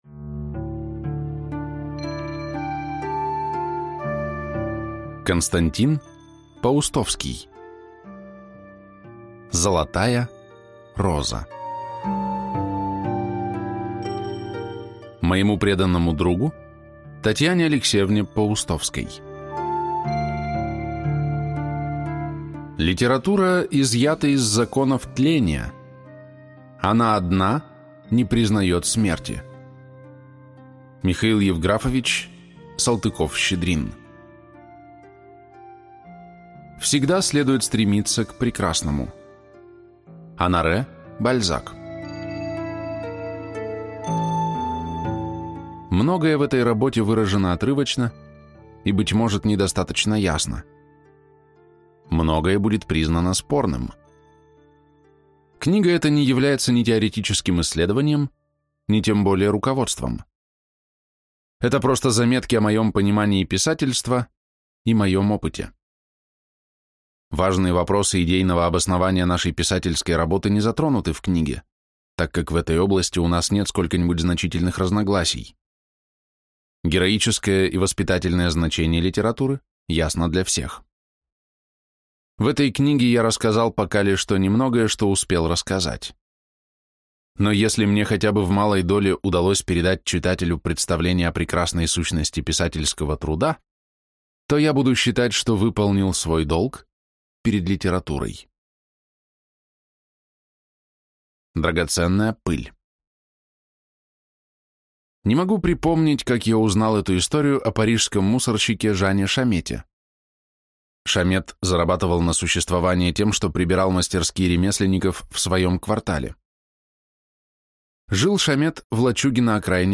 Аудиокнига Золотая роза | Библиотека аудиокниг